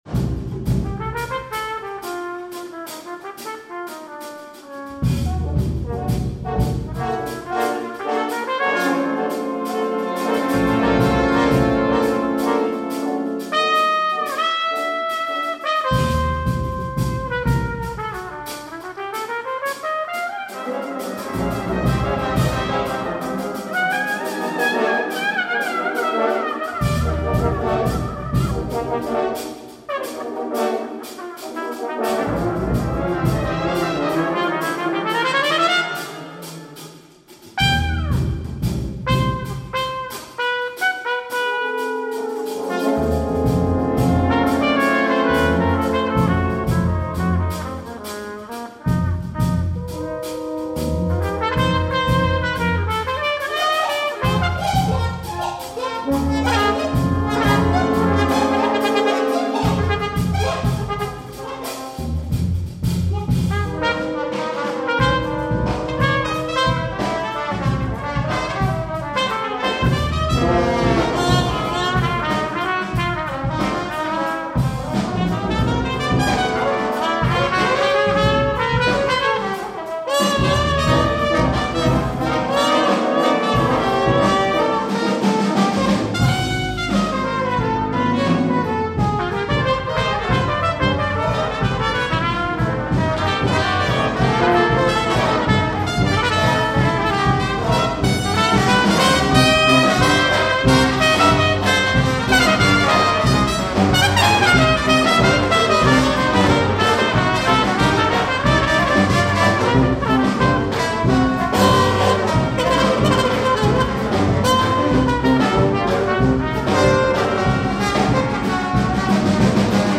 Established in 1997, Seaside Brass is a sextet of brass and percussion instrumentalists well versed in a variety of styles.